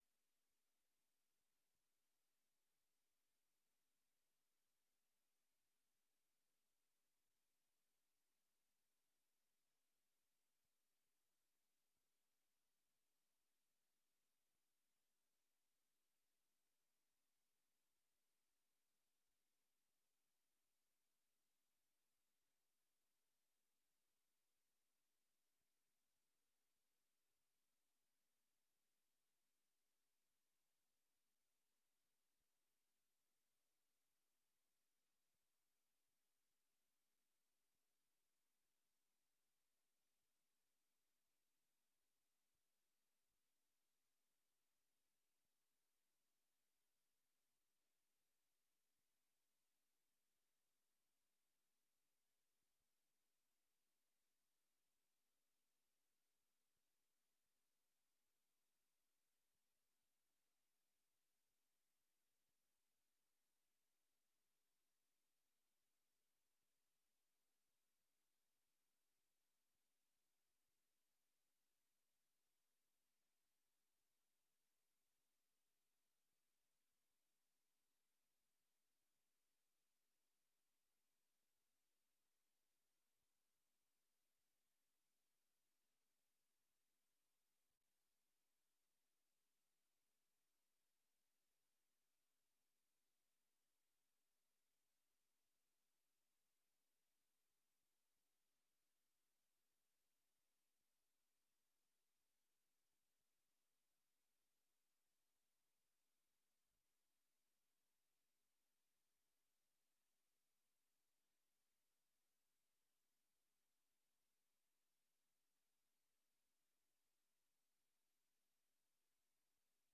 Raadsvergadering 11 mei 2023 19:30:00, Gemeente Dronten
Download de volledige audio van deze vergadering